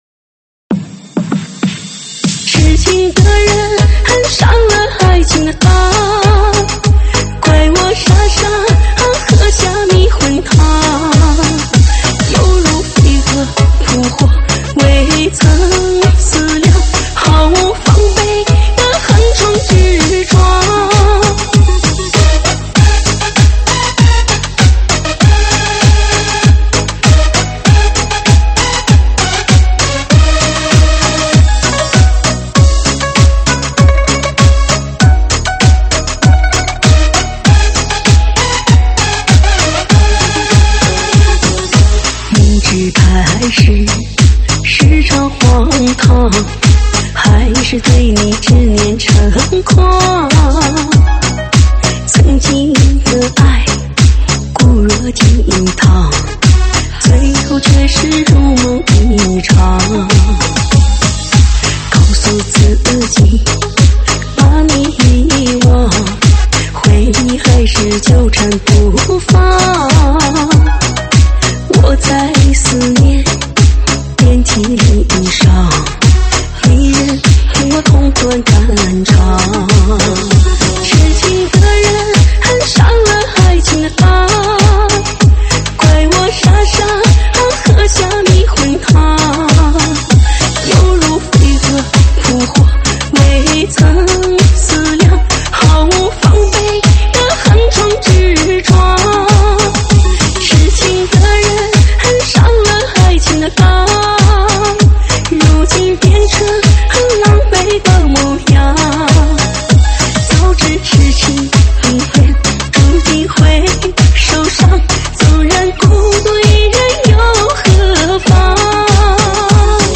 舞曲类别：吉特巴